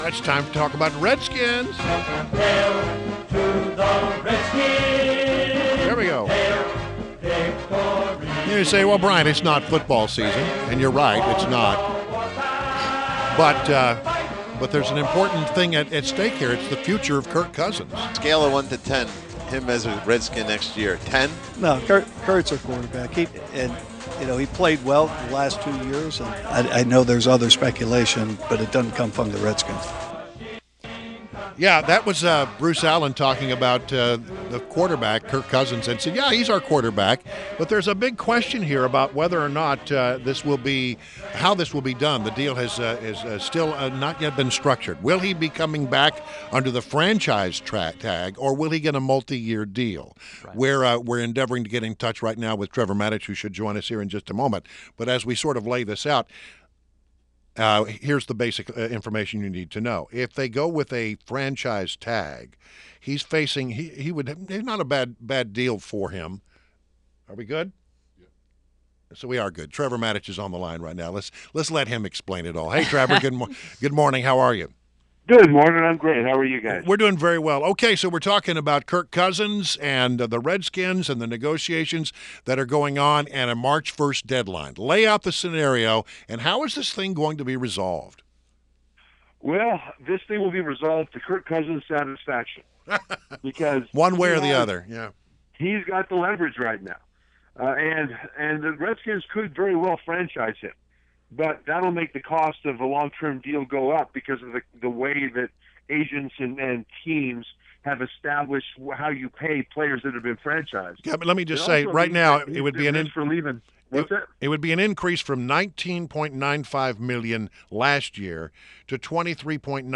WMAL Interview - TREVOR MATICH - 02.15.17
WMAL's Redskins analyst Trevor Matich on WMAL